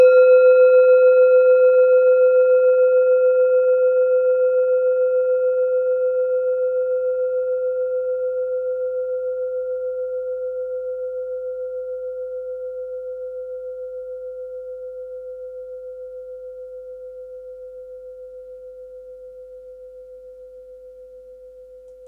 Kleine Klangschale Nr.23 Nepal
Planetentonschale: Sonnenton
Sie ist neu und wurde gezielt nach altem 7-Metalle-Rezept in Handarbeit gezogen und gehämmert.
Hörprobe der Klangschale
(Ermittelt mit dem Minifilzklöppel)
Die Frequenz des Sonnentons liegt bei 126,2 Hz und dessen tieferen und höheren Oktaven. In unserer Tonleiter ist das nahe beim "H".
kleine-klangschale-23.wav